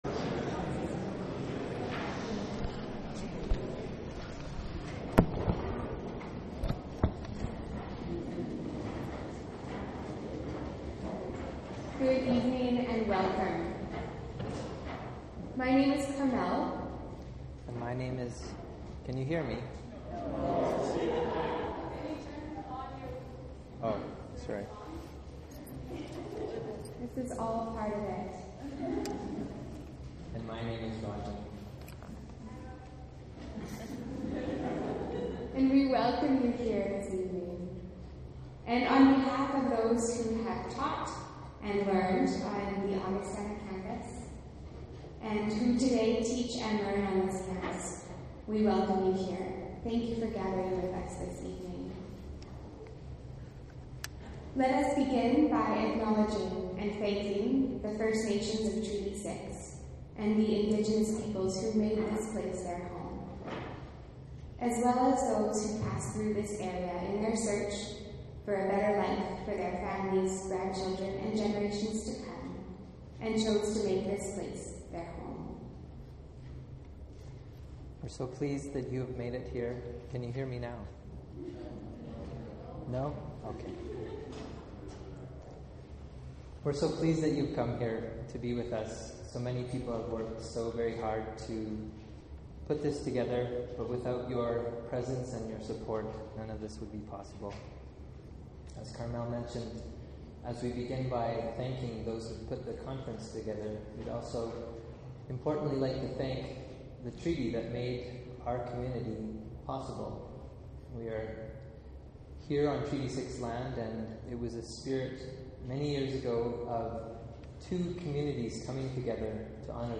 It was a gathering of creativity, prayer, music, art, media, ceremony, and bodies that packed the Augustana chapel to capacity.